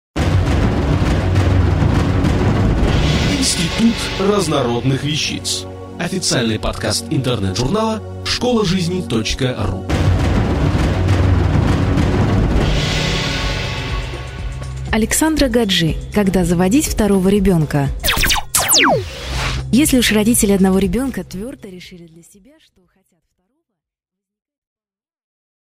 Аудиокнига Когда заводить второго ребенка?